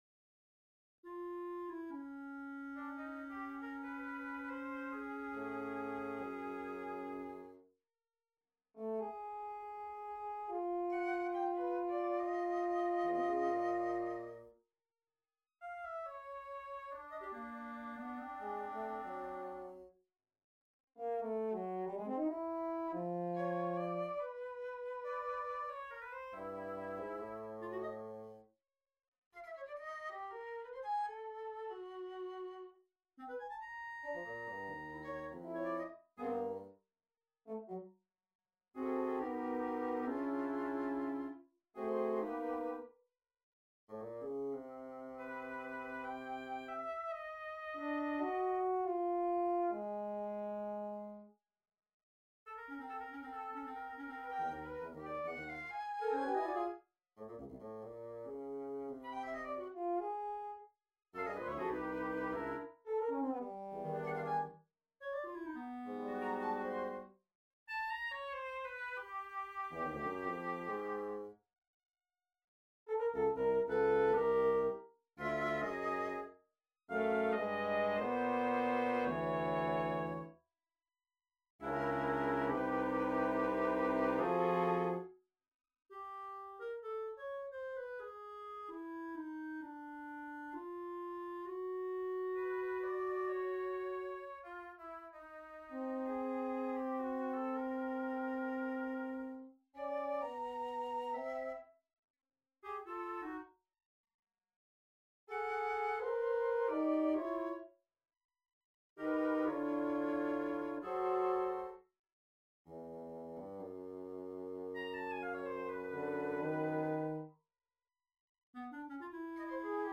Quintet for Winds on a purpose-selected tone row Op.52 1. Allegro - Meno mosso - Tempo I - Piu mosso - Tempo I - Tempo II 2. Scherzando con moto - Molto meno mossso - Molto piu mosso 3.
Presto molto Date Duration Download 19 February 2016 25'54" Realization (.MP3) Score (.PDF) 37.3 MB 644 KB